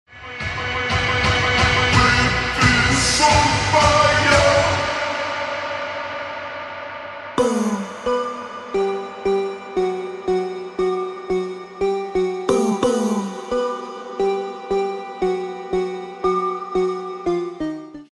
trap , ремиксы